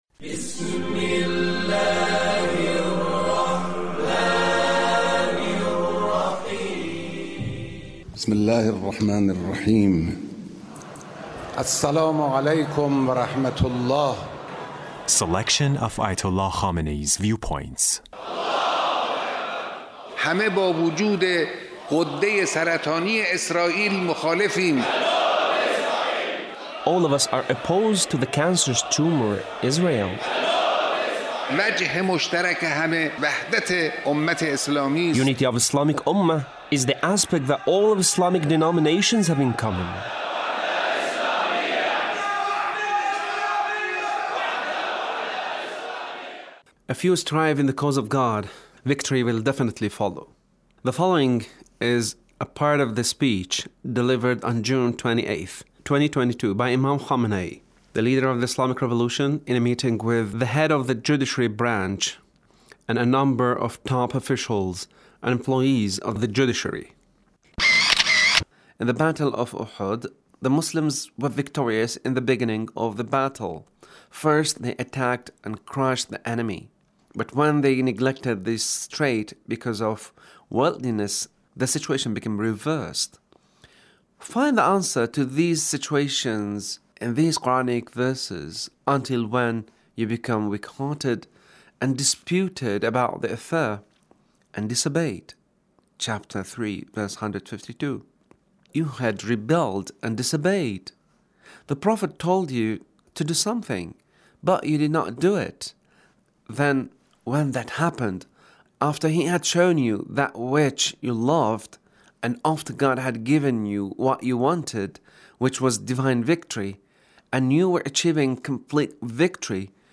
Leader's Speech with Judiciary Officials